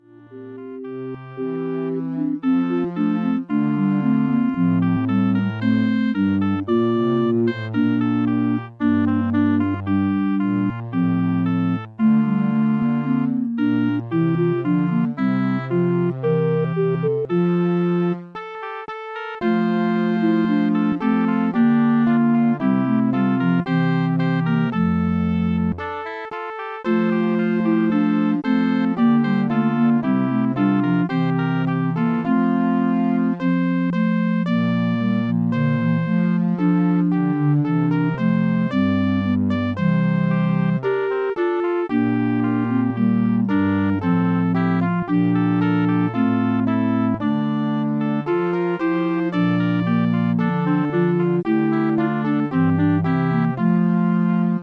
Voice and quartet or trio